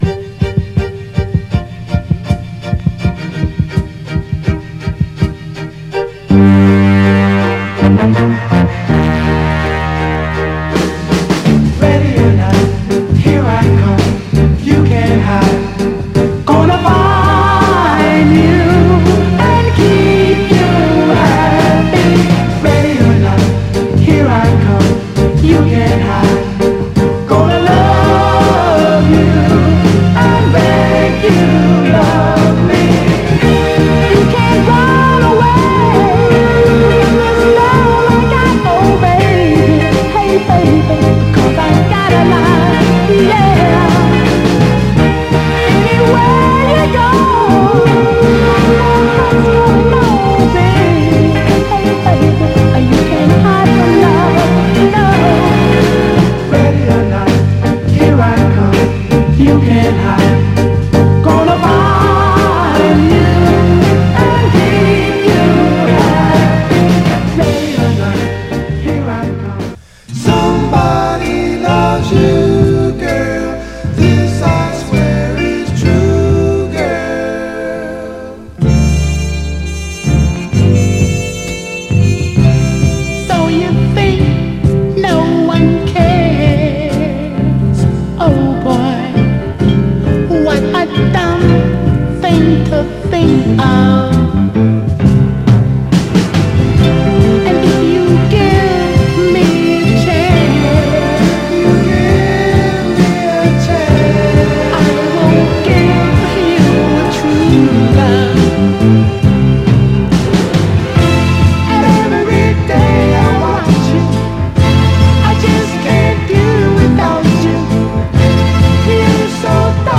盤はエッジ中心に細かいスレ、細かいヘアーラインキズ細かい小キズ箇所ありますが、音への影響は少なくプレイ良好です。
※試聴音源は実際にお送りする商品から録音したものです※